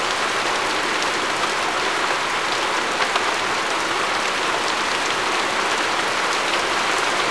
Rain3.wav